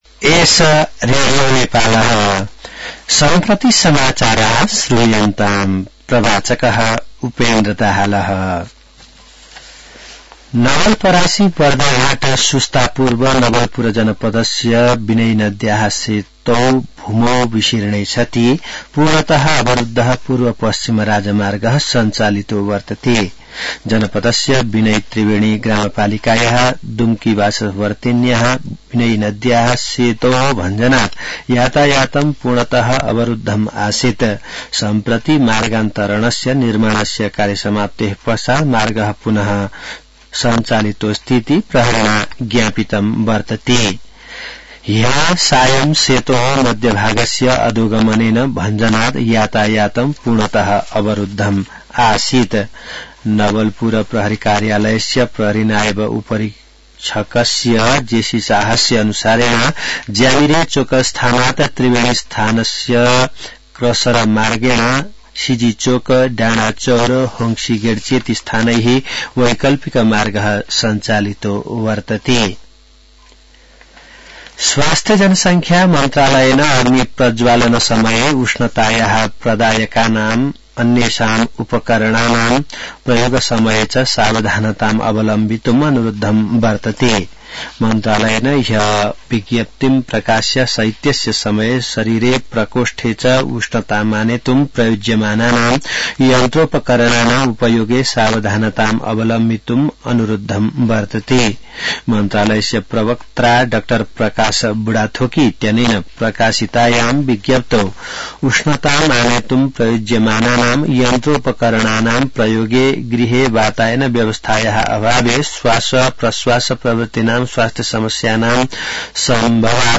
संस्कृत समाचार : २८ पुष , २०८१